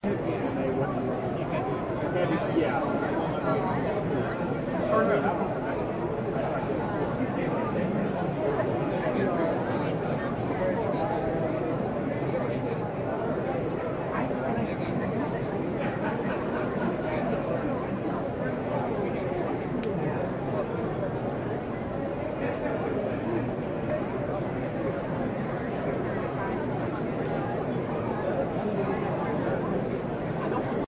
stadium.ogg